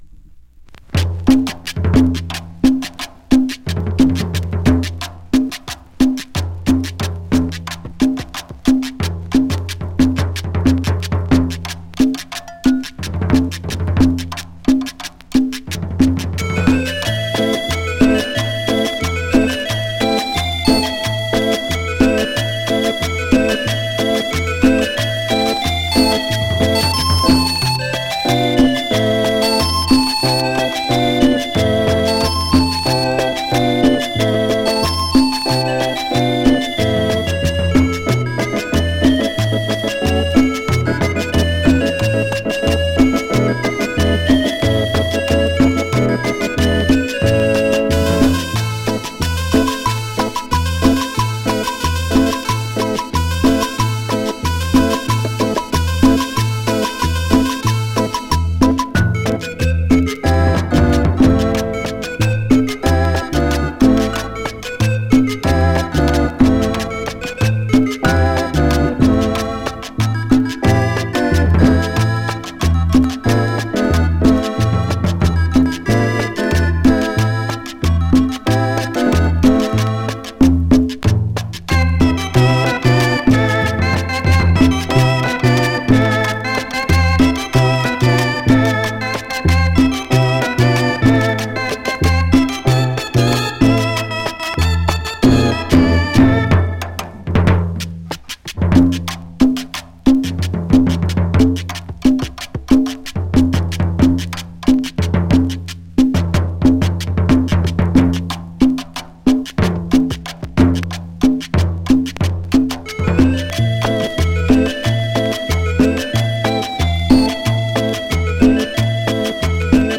cover song